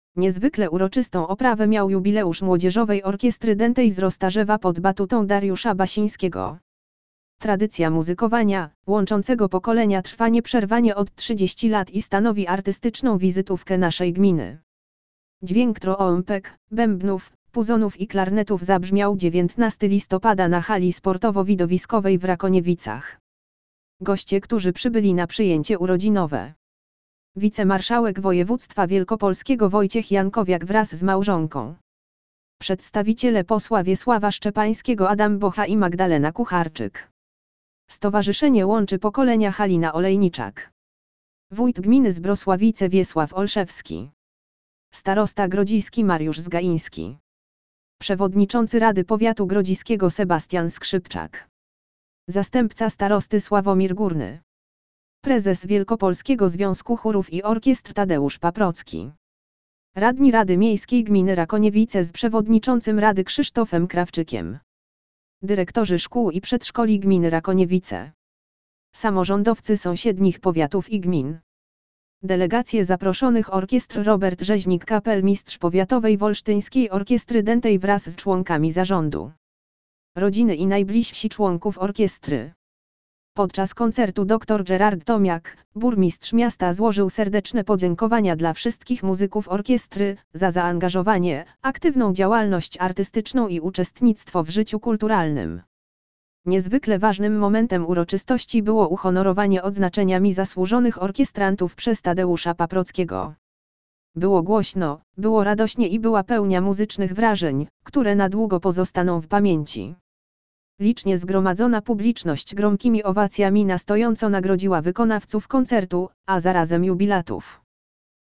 Dźwięk trąbek, bębnów, puzonów i klarnetów zabrzmiał 19 listopada na Hali Sportowo Widowiskowej w Rakoniewicach.